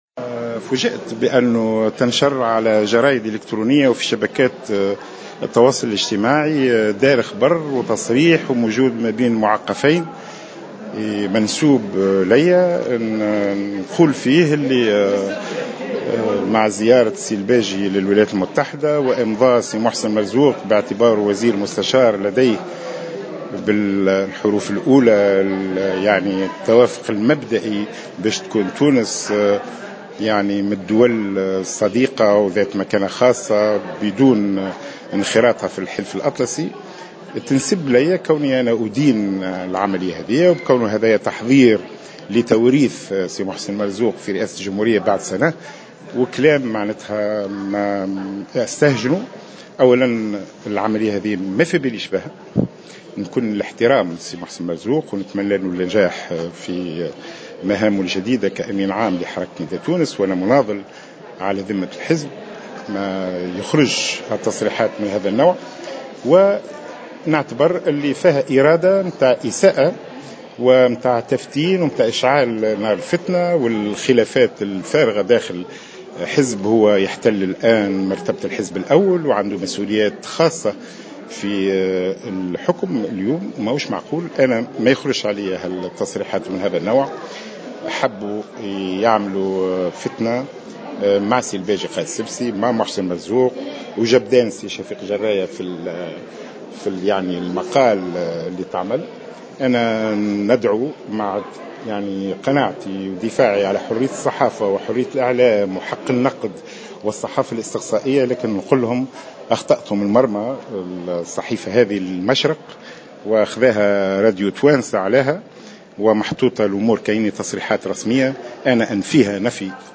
أكد النائب بمجلس النواب عن حركة نداء تونس خميس قسيلة في تصريح لمراسل جوهرة "اف ام" اليوم الجمعة 24 جويلية 2015 أن تفاجئ بتصريح منسوب بخصوص زيارة الباجي قايد السبسي ومحسن مرزوق للولايات الامريكية المتحدة .